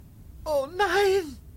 Download oh nein! Sound effect Button free on sound buttons.